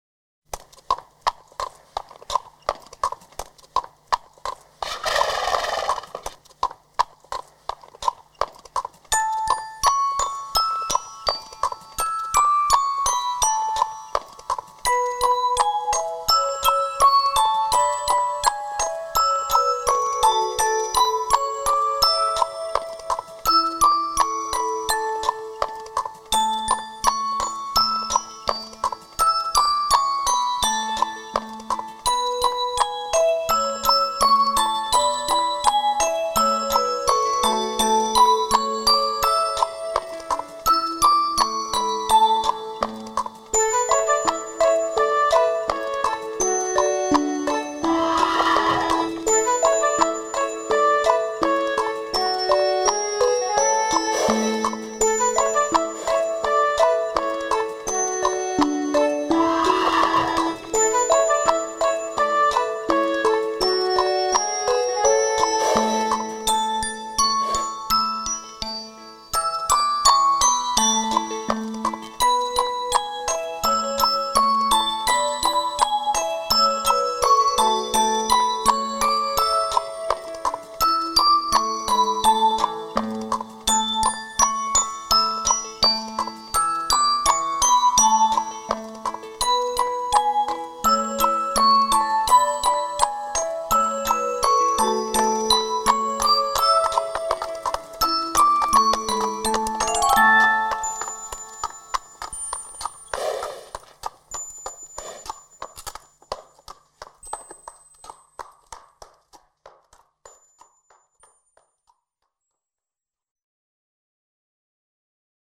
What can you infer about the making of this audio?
3D Spatial Sounds